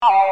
Free MP3 vintage Sequential circuits Pro-600 loops & sound effects 4